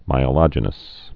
(mīə-lŏjə-nəs) also my·e·lo·gen·ic (-lə-jĕnĭk)